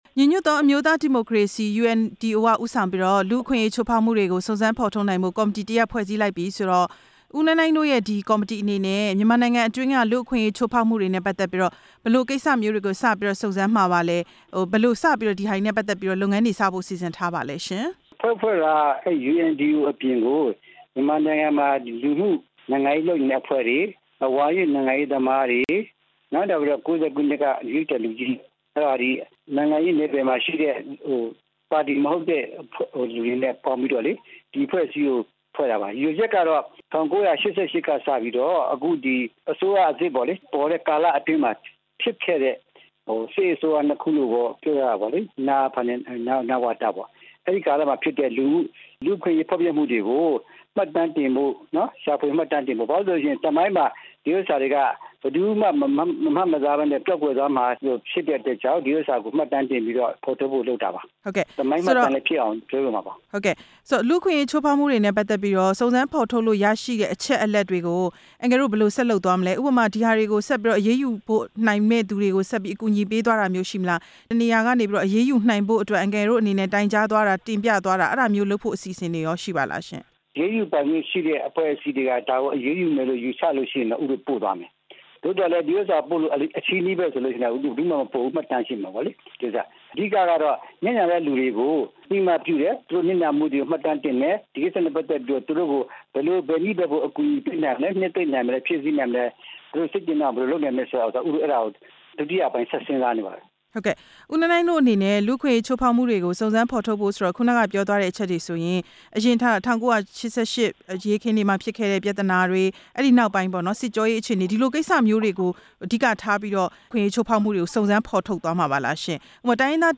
လူ့အခွင့်ရေးချိူးဖောက်မှု စုံစမ်းဖော်ထုတ်ရေး ကော်မတီ အကြောင်း မေးမြန်းချက်